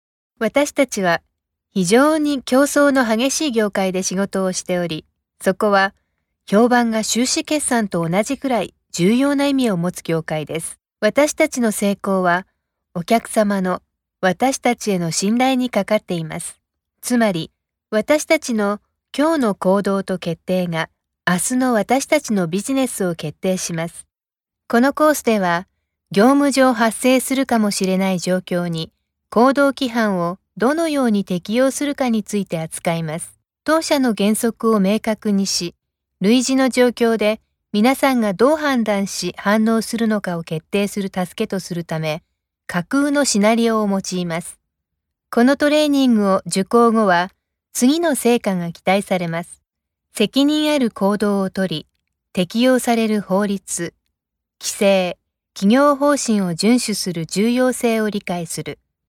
japanese,voiceover,narration,animation,corporate,video,advertising,character voices,voice over,broadcasting
Sprechprobe: eLearning (Muttersprache):